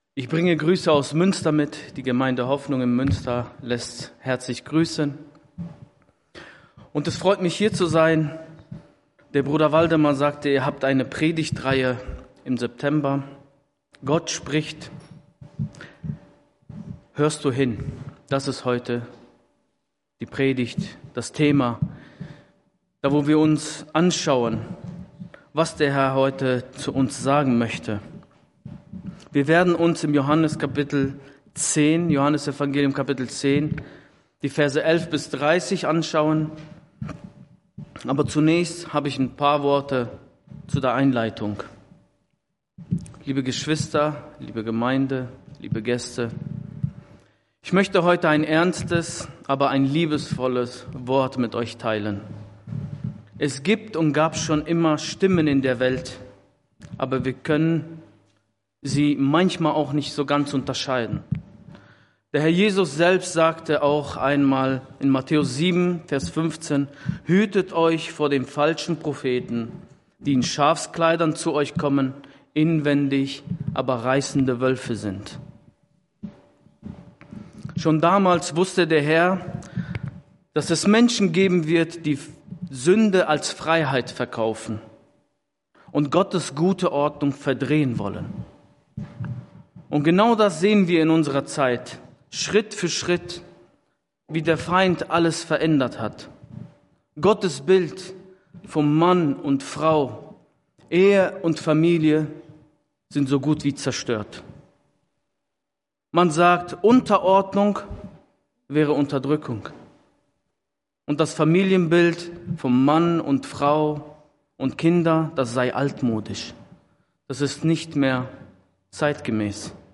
Verknüpfte Predigten